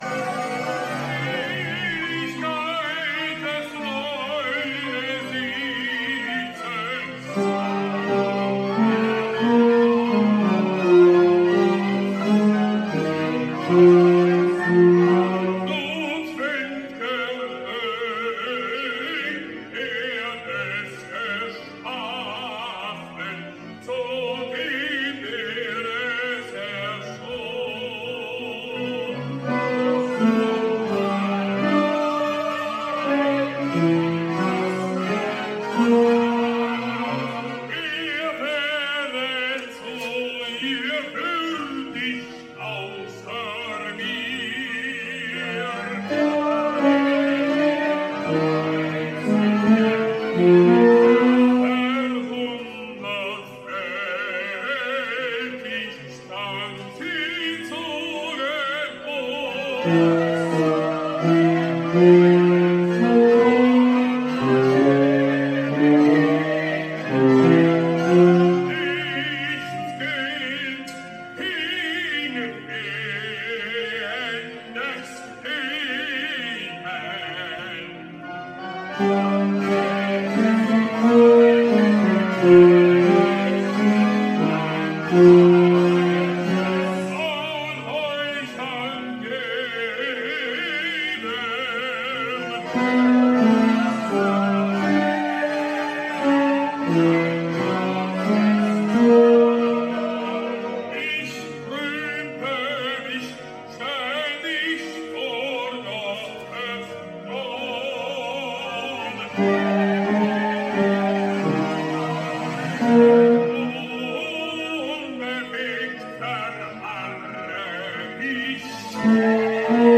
FRAGMENTY Z DOGRANYM GŁOSEM
* - a capella
Nr_17_4.59-basy.mp3